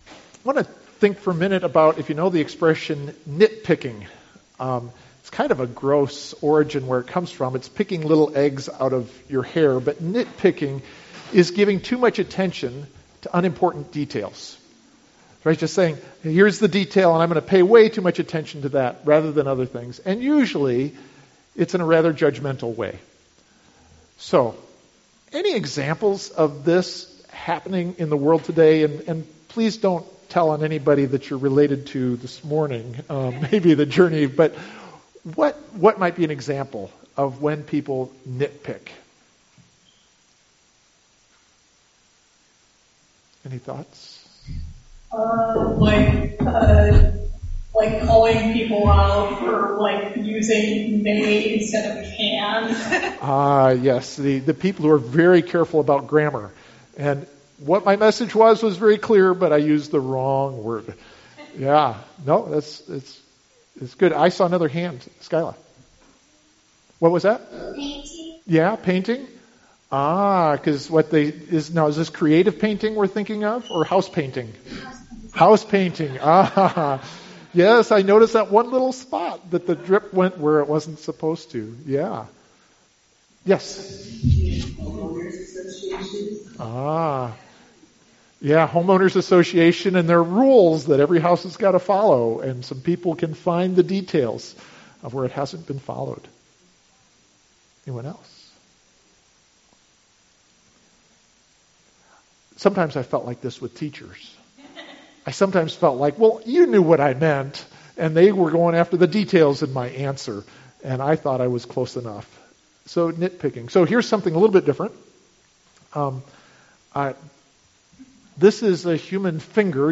CMC Sermon Handout